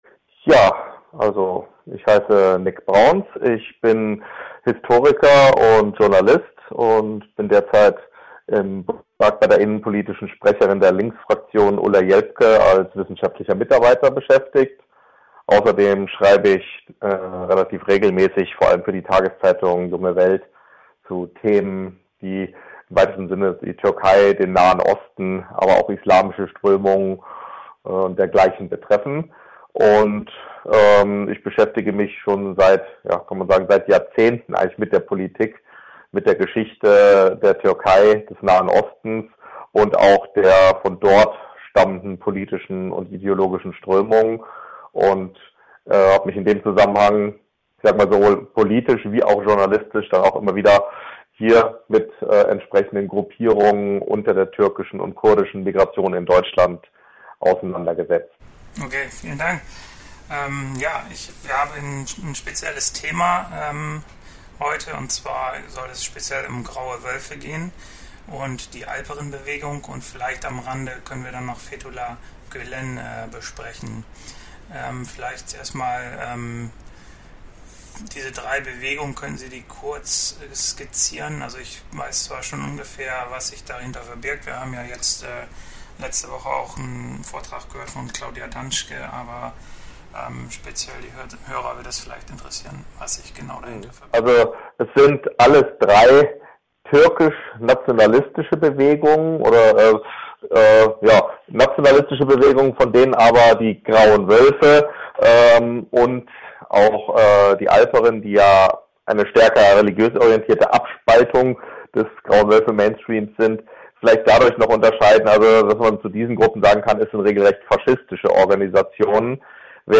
Die Juli-Sendung der Initiative für ein Freies Radio in Neumünster war am 10. Juli 2015 um 15 Uhr im Freien Sender Kombinat FSK in Hamburg auf der 93,0 MHz zu hören.